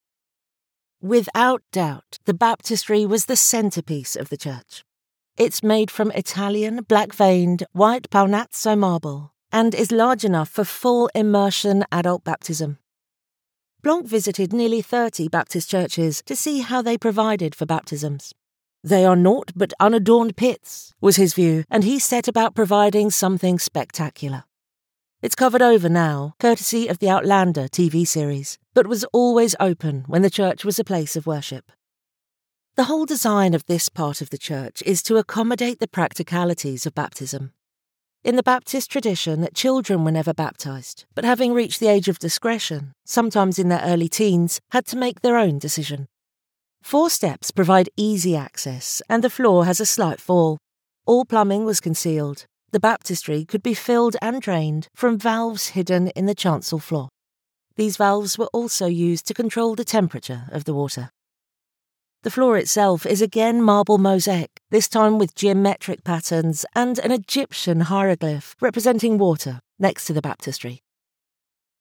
Natural, Llamativo, Accesible, Versátil, Cálida
Audioguía